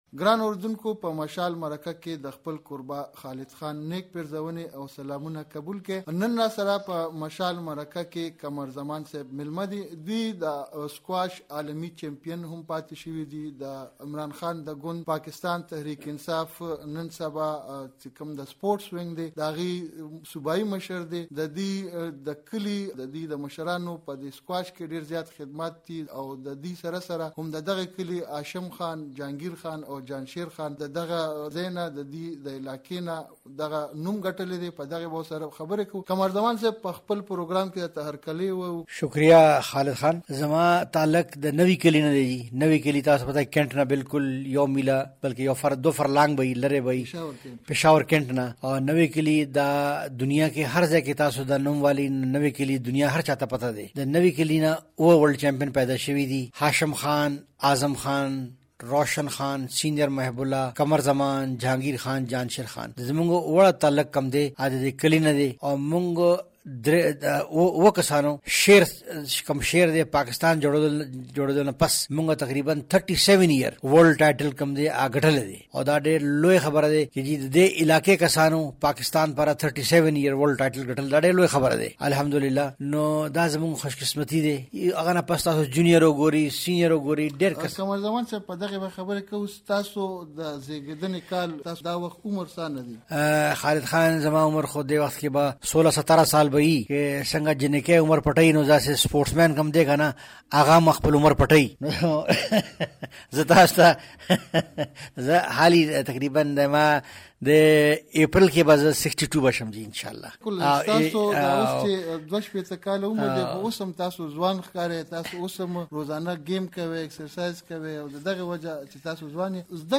دسکواش جهاني اتل قمرزمان سره مرکه
دمشال مرکه